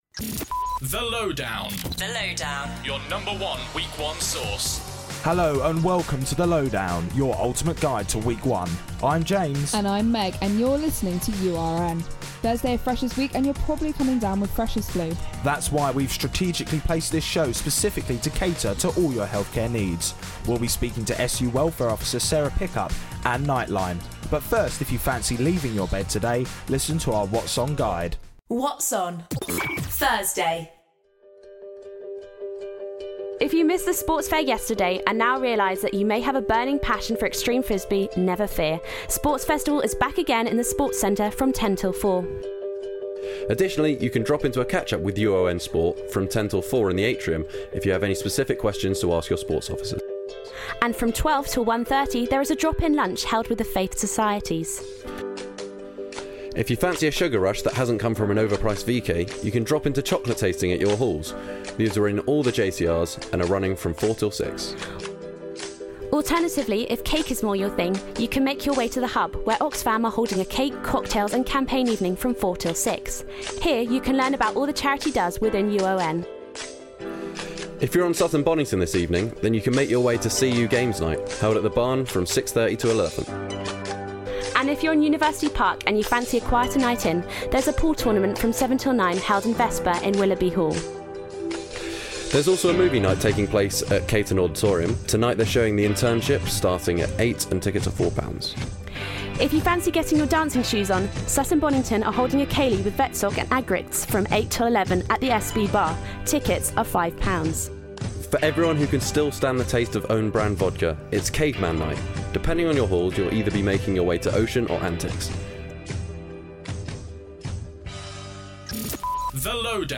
You're definitive guide to Welcome Week. Including interviews